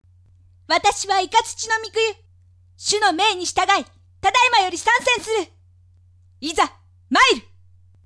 ・のんびりした優しい性格で、少し語尾が延びる。
【サンプルセリフ】